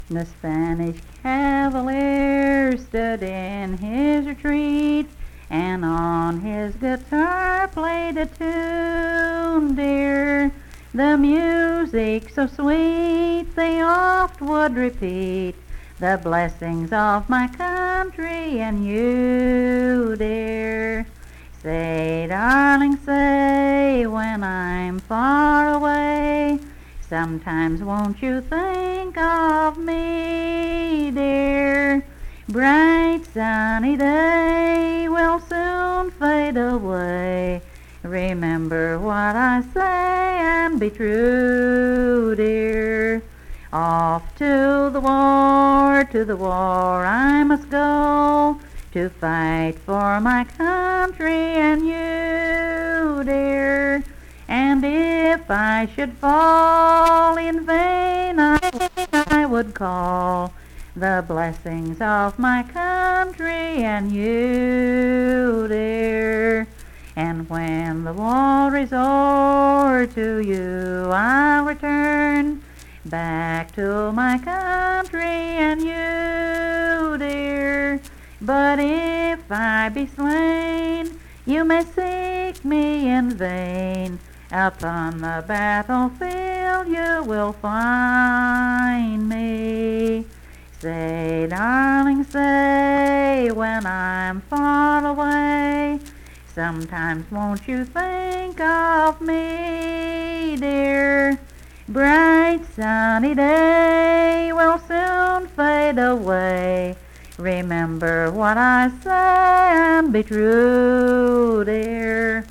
Unaccompanied vocal music performance
Verse-refrain 3(4) & R(4).
Voice (sung)